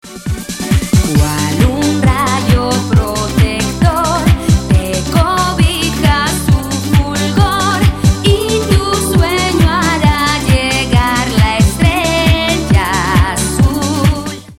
Version Dance